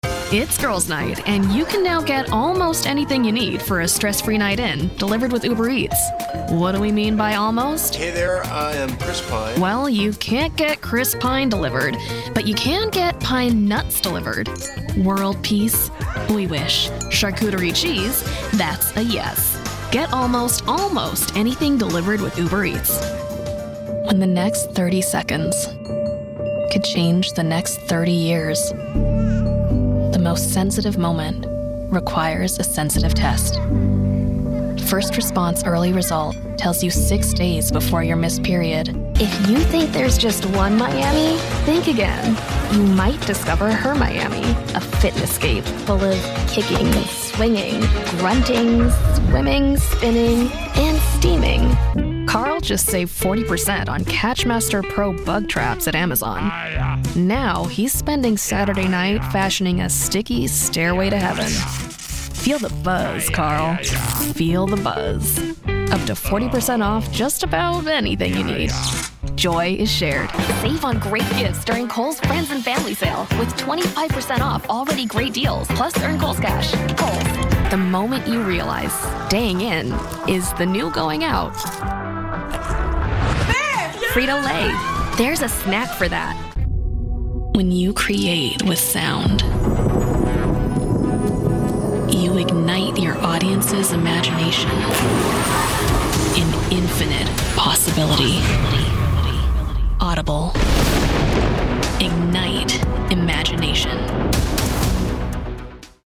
Commercial Demo
I record from my professional home studio with my Synco D2 microphone, SSL2 interface, and TwistedWave DAW on my MacBook Pro.